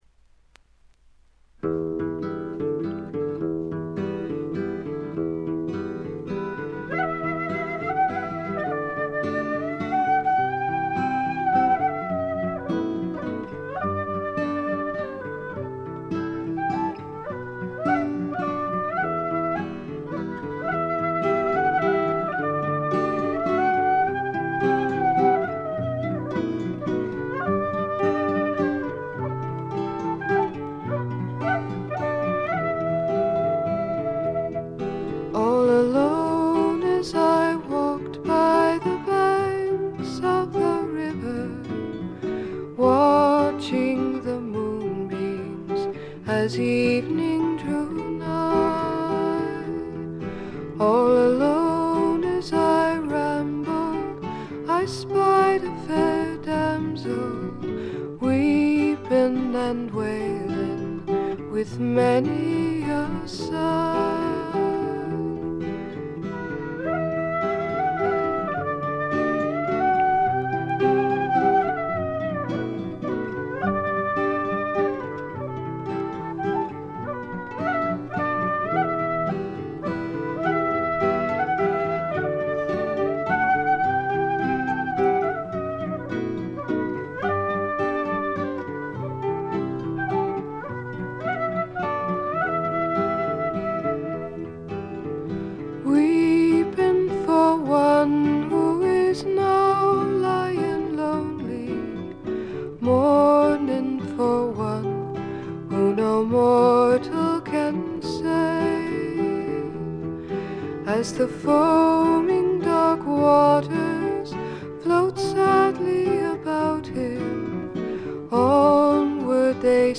ちょっとハスキーな美声ではかなげに歌われる宝石のような歌の数々。
試聴曲は現品からの取り込み音源です。
guitar
flute
dobro guitar